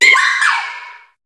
Cri de Mimiqui dans Pokémon HOME.